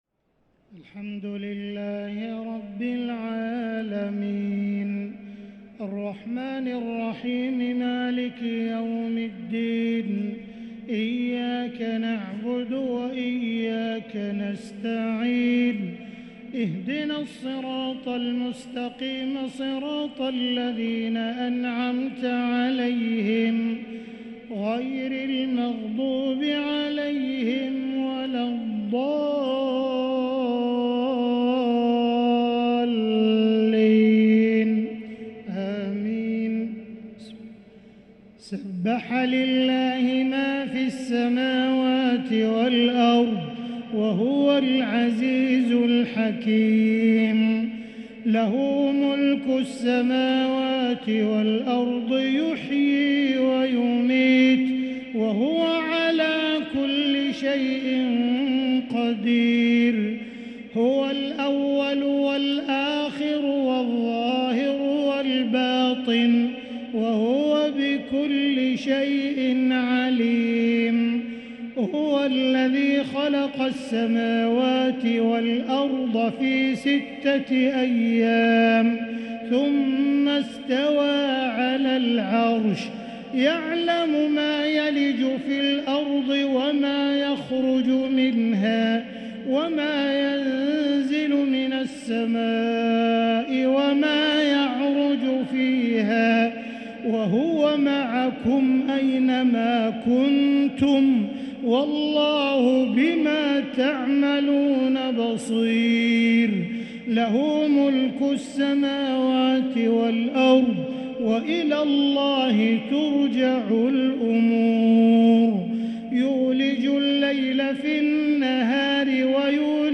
تهجد ليلة 27 رمضان 1444هـ سورة الحديد كاملة | Tahajjud 27 st night Ramadan 1444H from Surah Al-Hadid > تراويح الحرم المكي عام 1444 🕋 > التراويح - تلاوات الحرمين